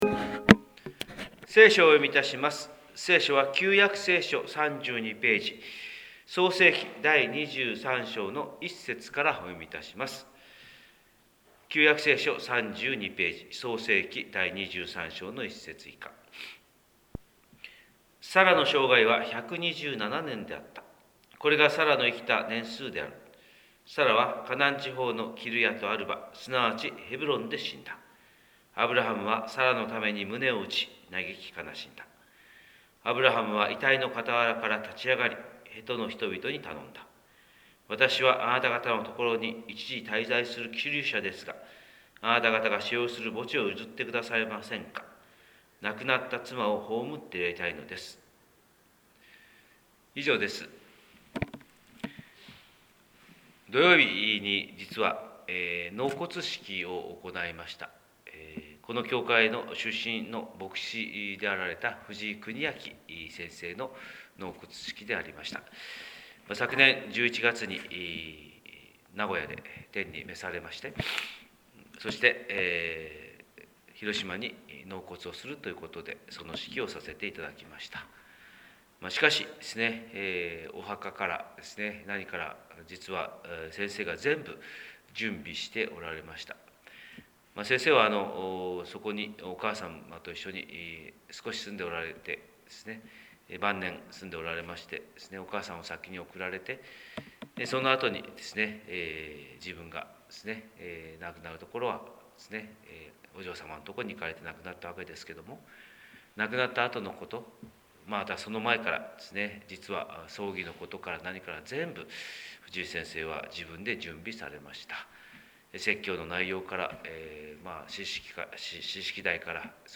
神様の色鉛筆（音声説教）: 広島教会朝礼拝250319
広島教会朝礼拝250319「最後をきちんと」